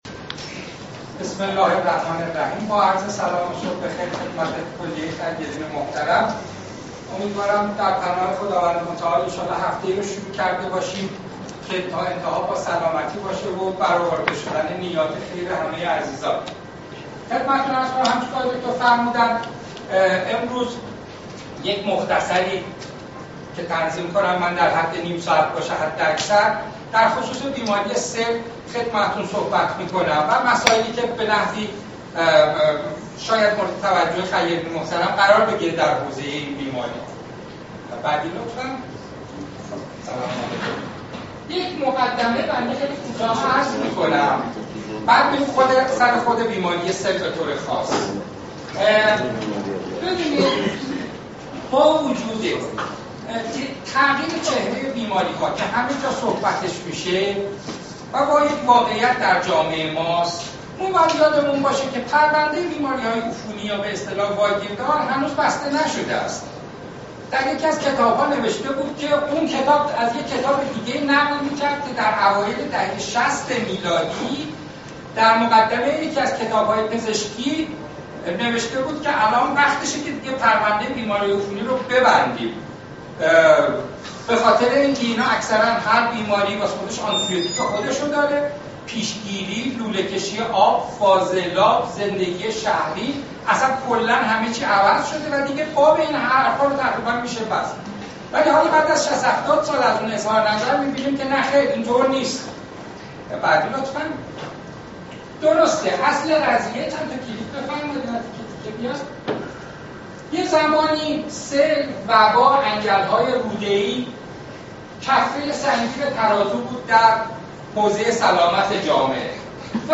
سخنرانی جلسه عمومی مجمع خیرین بهداشت و سلامت استان اصفهان